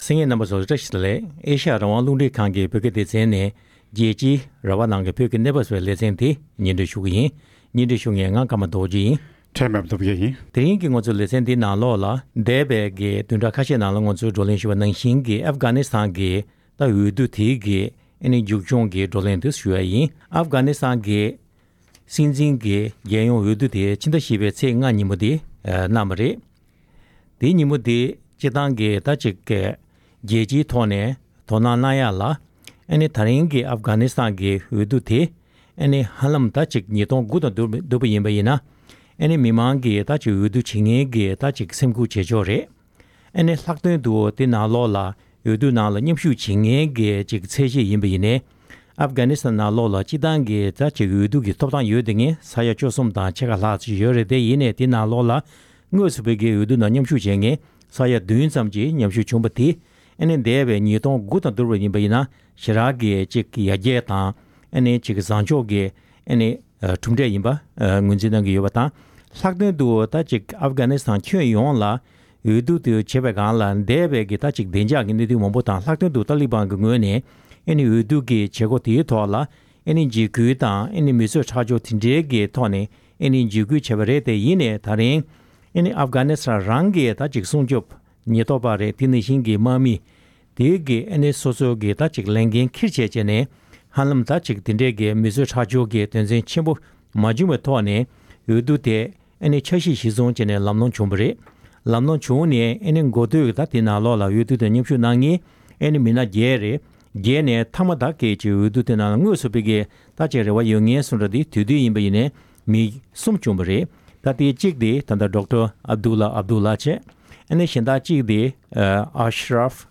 འོས་བསྡུ་བསྐྱར་དུ་བྱེད་དགོས་ཆགས་པའི་མཇུག་འབྲས་ཇི་ཆགས་ཐད་རྩོམ་སྒྲིག་འགན་འཛིན་རྣམ་པས་དཔྱད་གླེང་གནང་བ་དེ་གསན་རོགས་གནང་།།